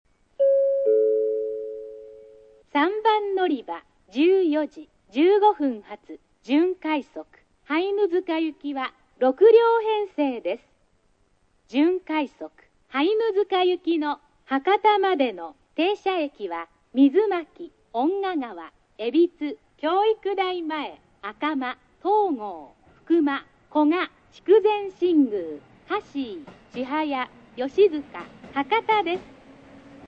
スピーカー： １、２、４、５番線、不明　　３番線、カンノ製作所　　６・７番線、TOA（放送なし）
音質：D〜E
３番のりば 案内放送 快速・大牟田　(146KB/29秒)